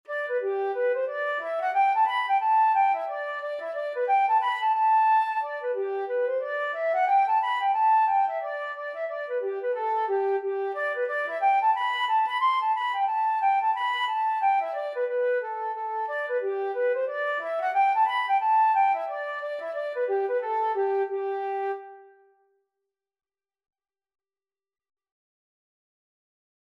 Traditional Trad. Arran Air (Irish Trad) Flute version
2/4 (View more 2/4 Music)
G5-C7
G major (Sounding Pitch) (View more G major Music for Flute )
Flute  (View more Intermediate Flute Music)
Traditional (View more Traditional Flute Music)